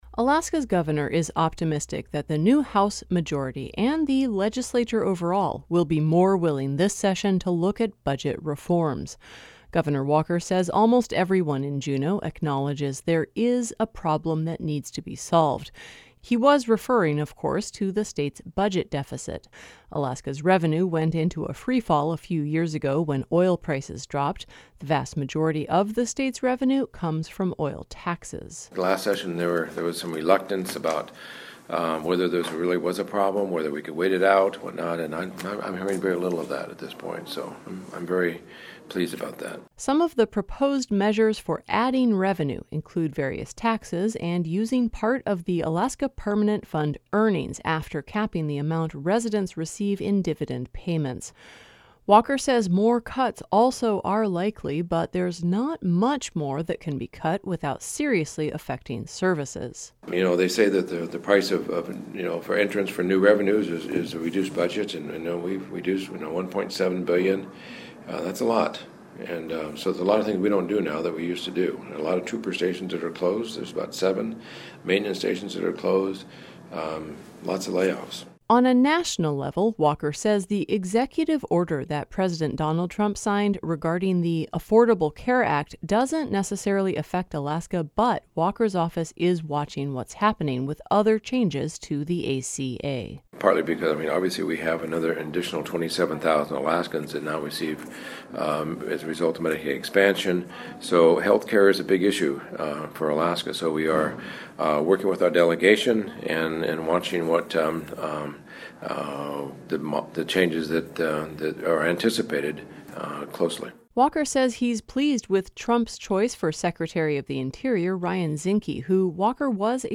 Before the event, he sat down with local media for a few minutes to talk about current issues facing the state.